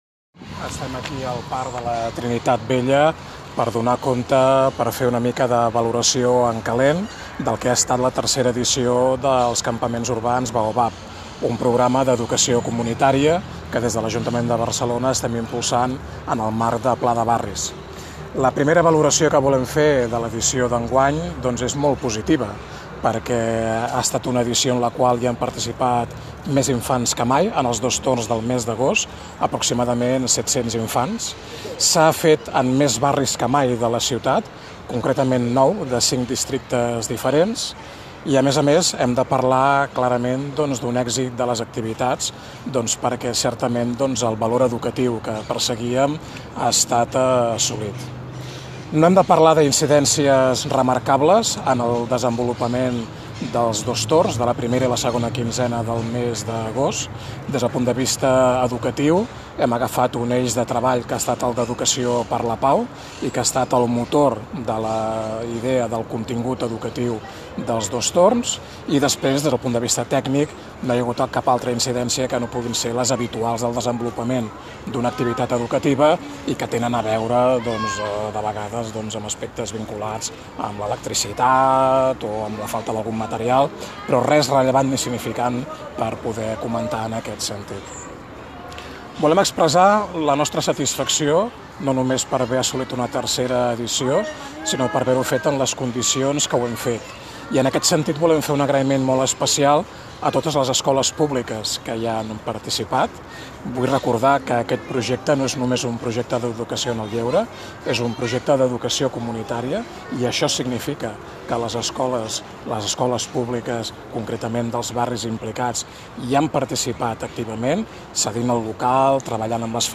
Declaracions del comissionat d’Educació, Infància i Joventut, Miquel Àngel Essomba (format m4a)